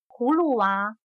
葫芦娃/Húlu wá/Bebé “calabaza”.